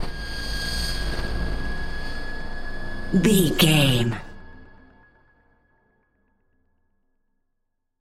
Low Alien Scream.
Alien Scream Low
Sound Effects
Atonal
scary
ominous
eerie
synthesiser